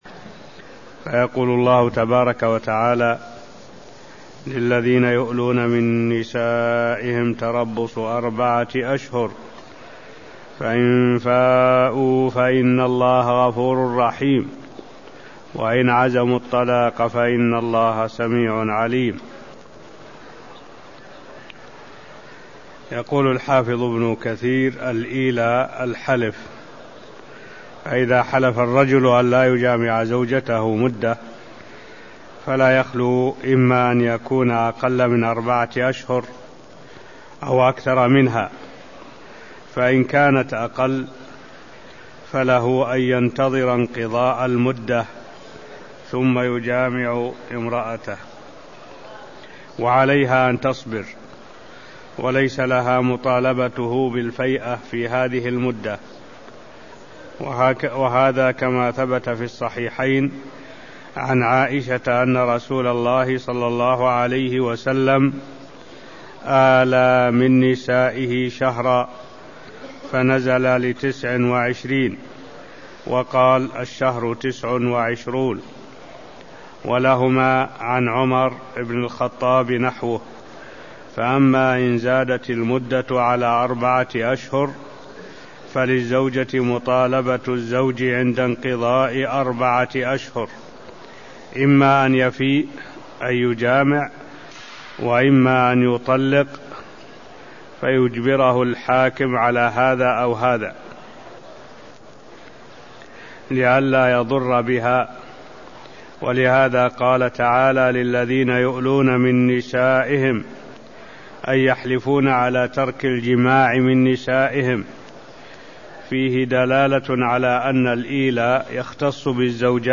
المكان: المسجد النبوي الشيخ: معالي الشيخ الدكتور صالح بن عبد الله العبود معالي الشيخ الدكتور صالح بن عبد الله العبود تفسي الآيات226ـ228 من سورة البقرة (0112)